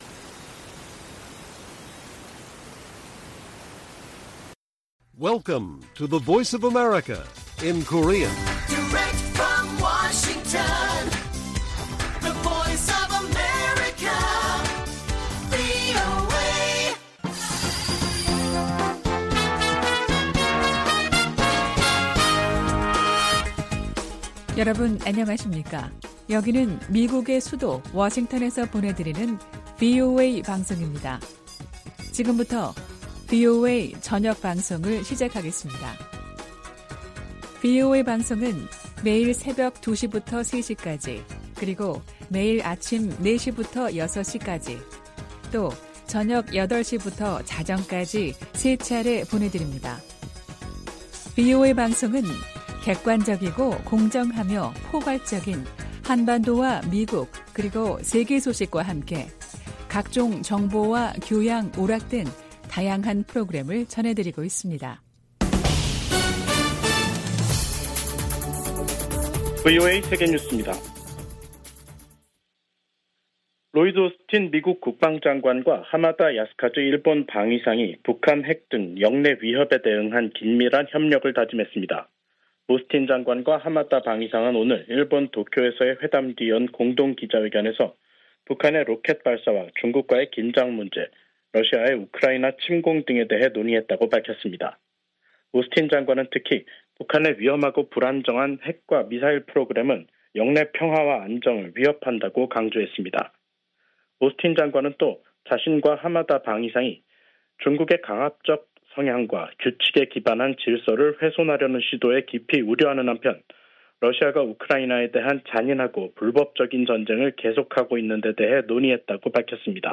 VOA 한국어 간판 뉴스 프로그램 '뉴스 투데이', 2023년 6월 1일 1부 방송입니다. 북한은 정찰위성 성공 여부와 관계 없이 군사 능력을 계속 발전시킬 것이라고 백악관 대변인이 말했습니다. 김여정 북한 노동당 부부장은 자신들의 군사정찰위성 발사를 규탄한 미국을 비난하며 위성 발사를 계속 추진하겠다고 밝혔습니다. 우주의 평화적 이용을 논의하는 유엔 회의에서 북한의 정찰위성 발사를 규탄하는 목소리가 나왔습니다.